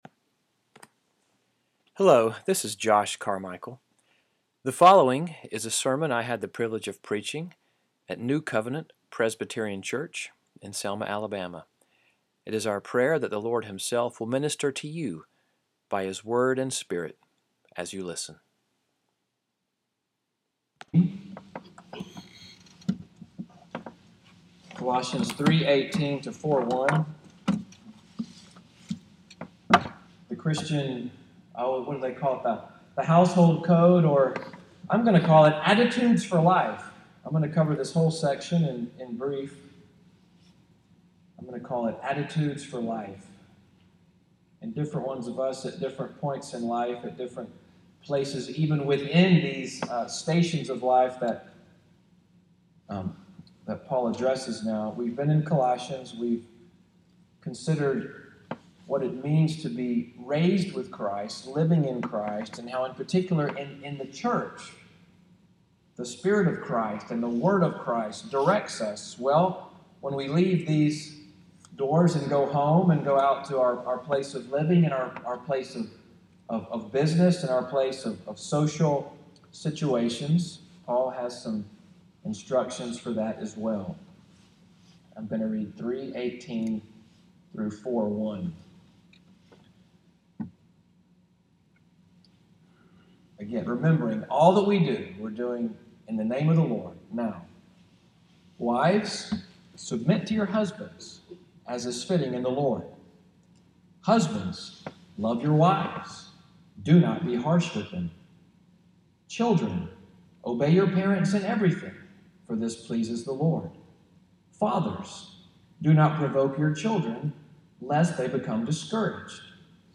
EVENING WORSHIP at NCPC, sermon audio, “Attitudes for Life“, April 2, 2017